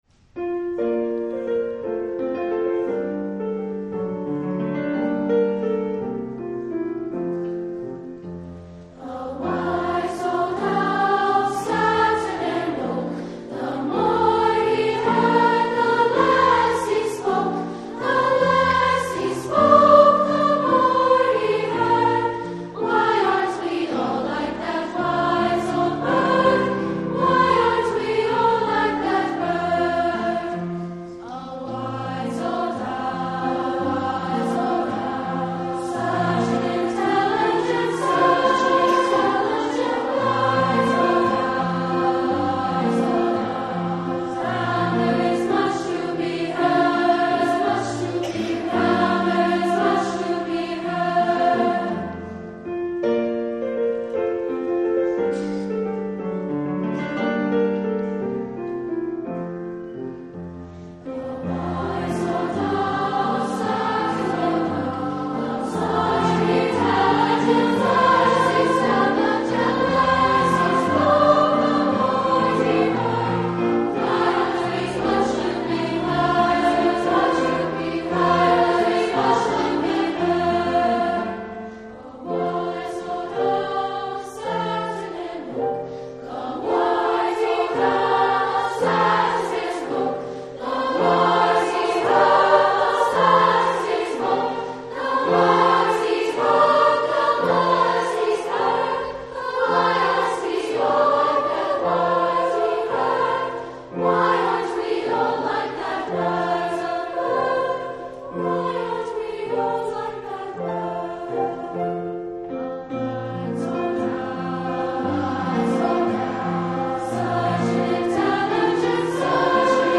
Voicing: 3-Part and Piano